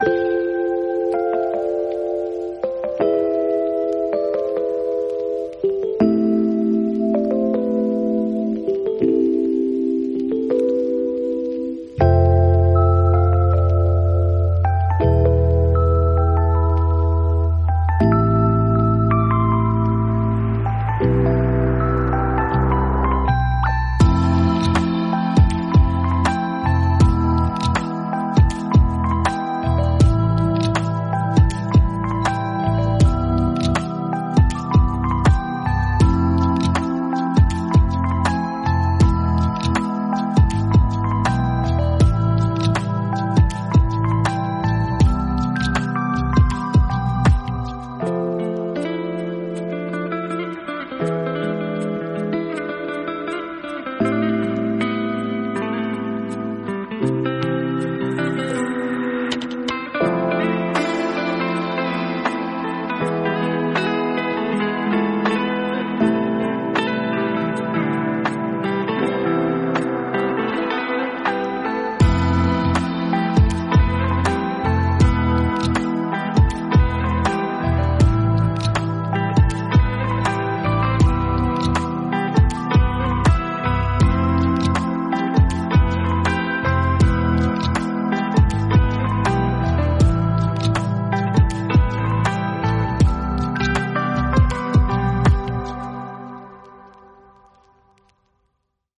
calming track